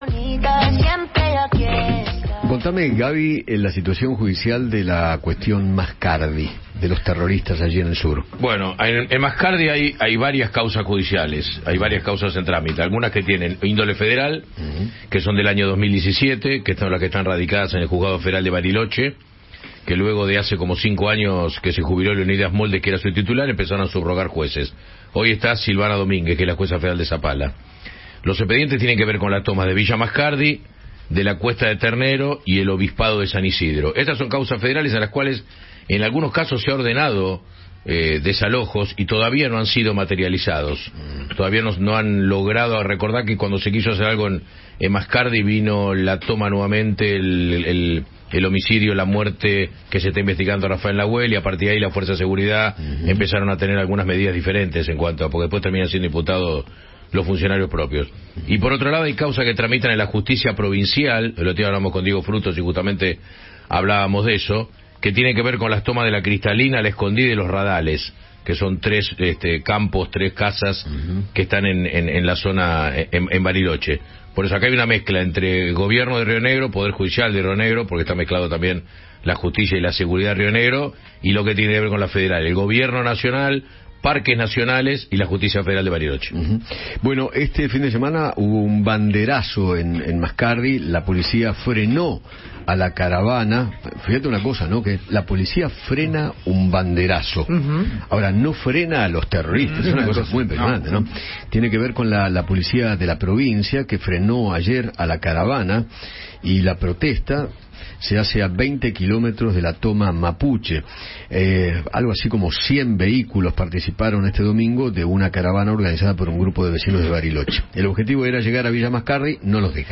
Juan Martín, diputado provincial de Río Negro, conversó con Eduardo Feinmann sobre las causas judiciales por las tomas en la Patagonia.